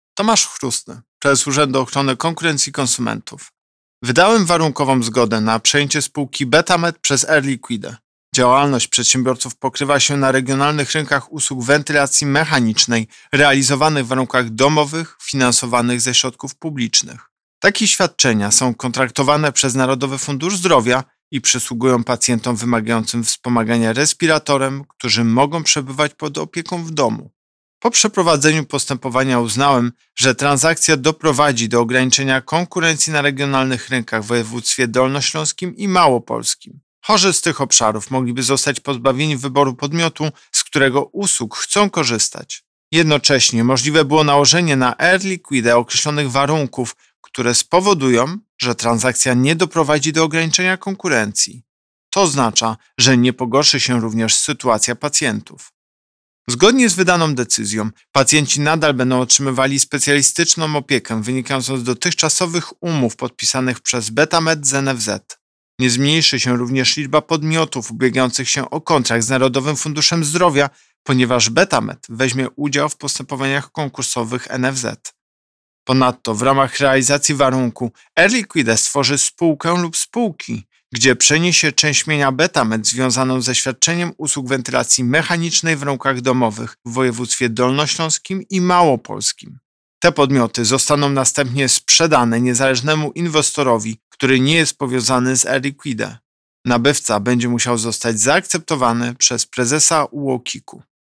Wypowiedź Prezesa UOKiK Tomasza Chróstnego z 7 września 2021 r..mp3